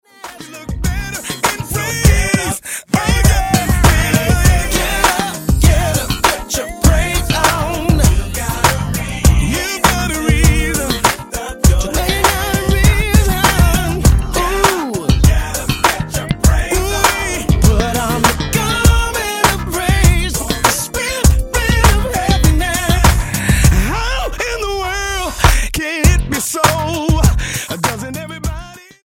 STYLE: Gospel
funky
a retro soul feel